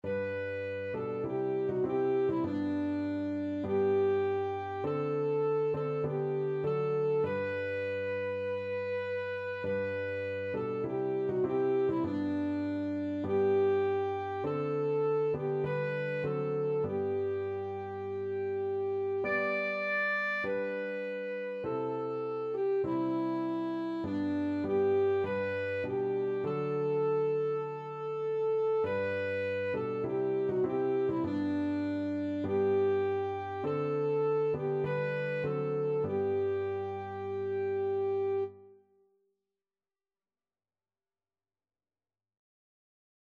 Alto Saxophone
G major (Sounding Pitch) E major (Alto Saxophone in Eb) (View more G major Music for Saxophone )
4/4 (View more 4/4 Music)
Classical (View more Classical Saxophone Music)